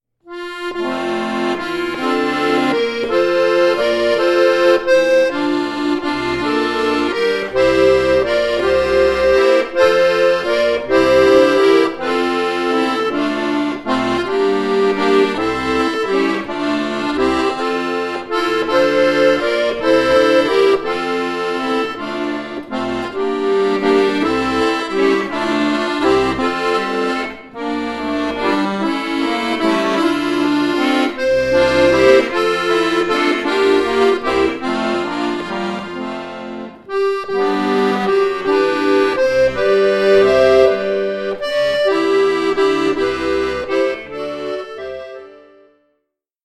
Akkordeon Solo
Lullaby